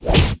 attack.ogg